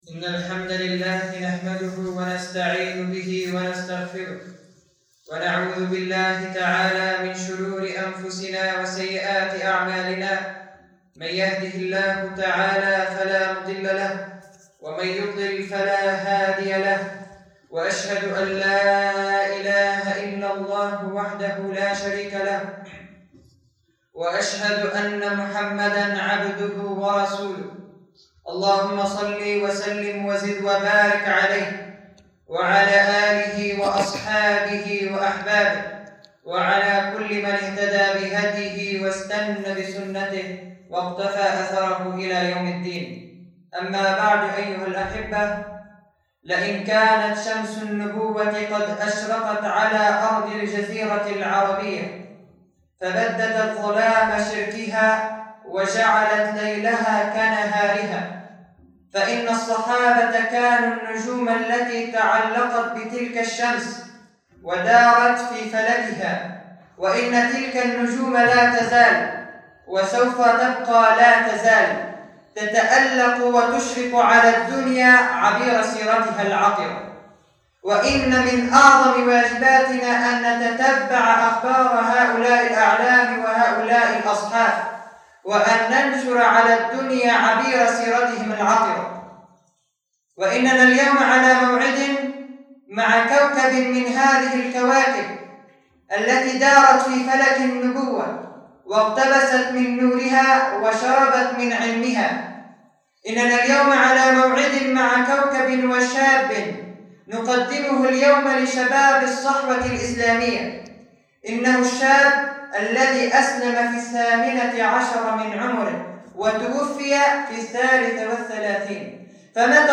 [خطبة جمعة] معاذ بن جبل رضي الله عنه – موقع بلدة القلمون في لبنان